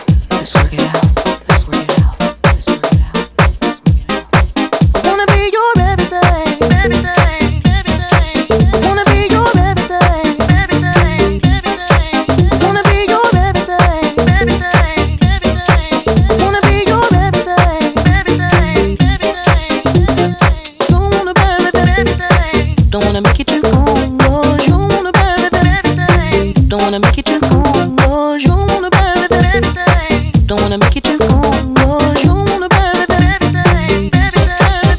# Garage